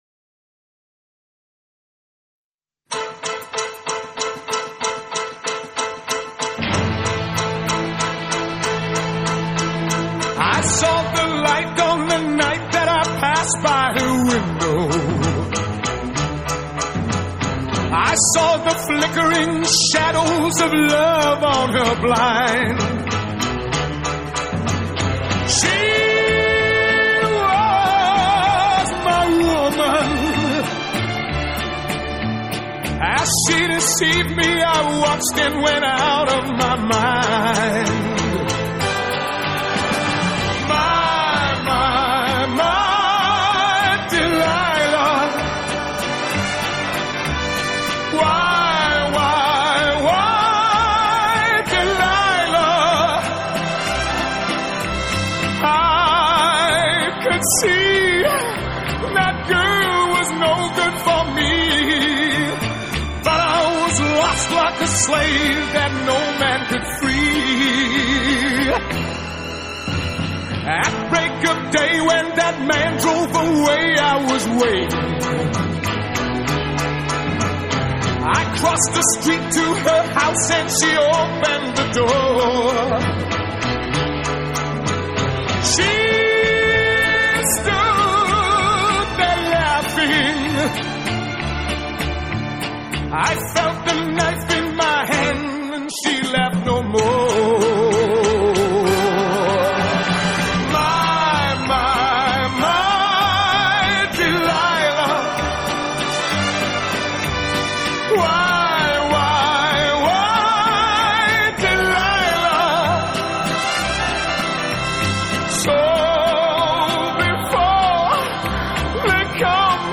Pop, Rock